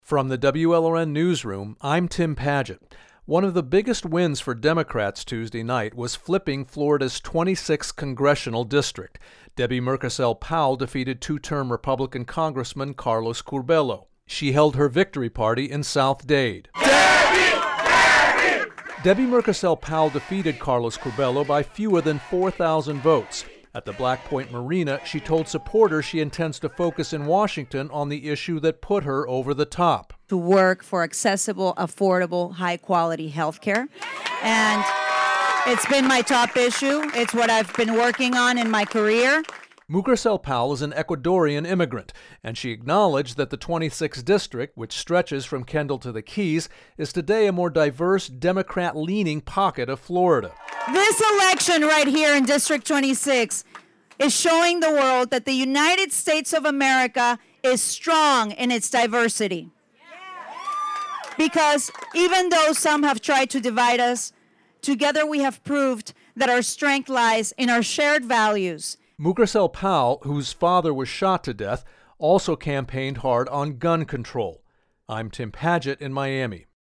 Florida 26th District Congresswoman-elect Debbie Mucarsel-Powell giving her victory speech at Black Point Marina in South Dade Tuesday night.
As supporters shouted "Deb-bie! Deb-bie!" at the Black Point Marina in South Dade, she said she intends to focus in Washington on the issue that put her over the top.